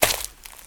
High Quality Footsteps
STEPS Leaves, Walk 21.wav